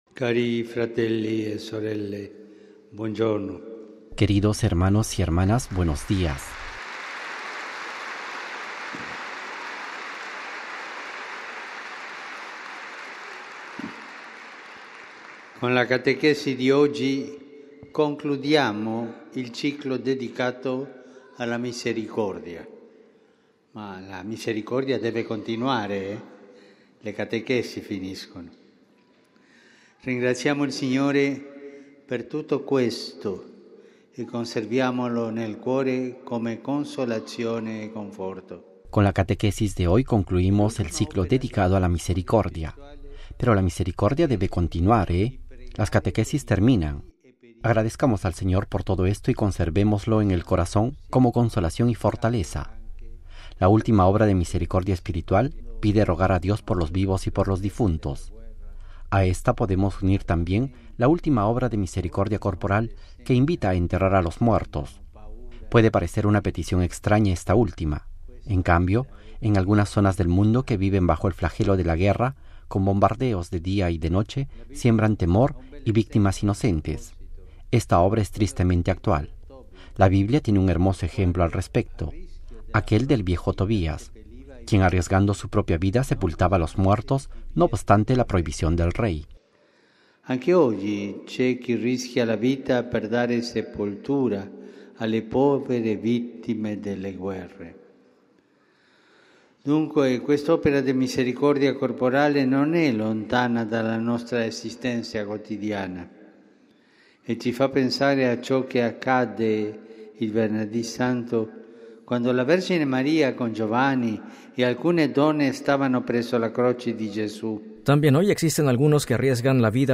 Texto completo y audio de la catequesis del Papa Francisco